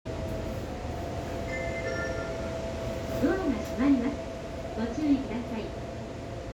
新101系車両搭載発車放送
簡単なチャイムの後に放送が流れる仕組み。ワンマン区間ではほぼ必ず使用されるようです。
余談ながら、多摩湖線国分寺駅の発車ベルがこれと殆ど同じ放送だったりします。